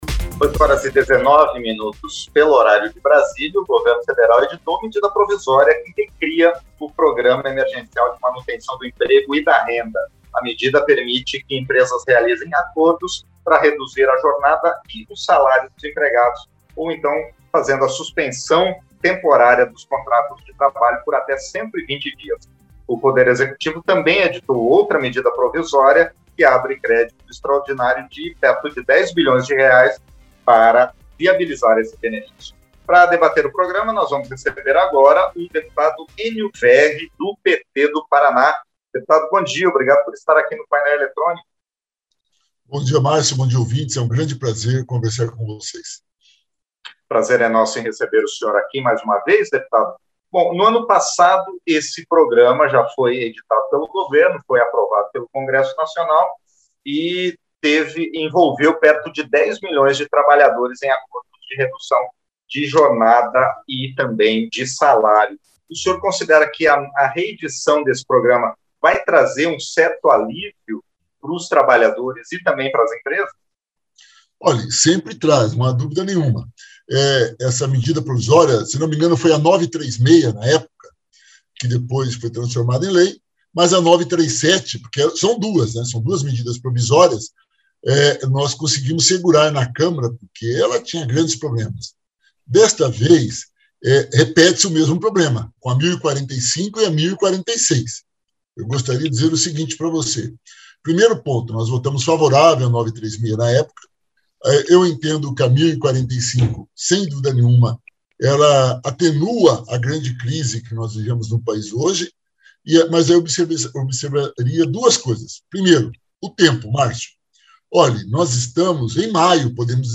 Entrevista - Dep. Enio Verri (PT-PR)